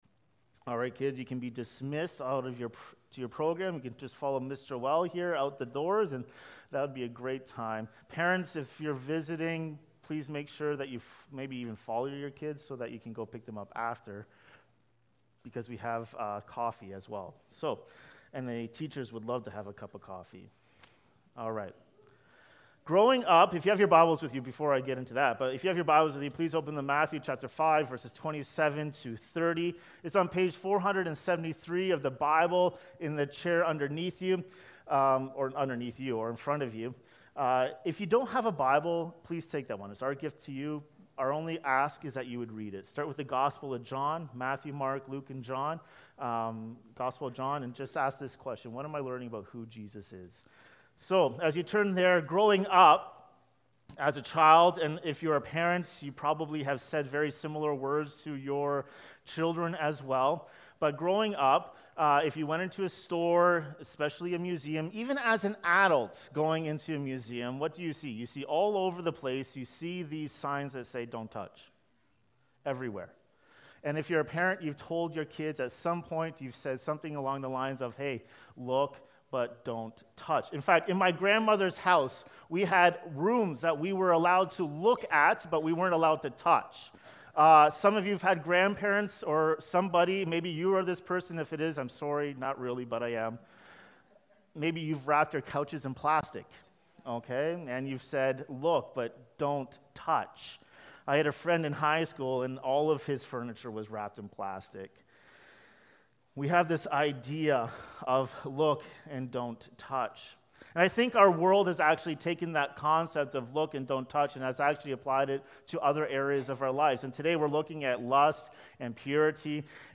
A sermon on heart transformation, repentance, and grace.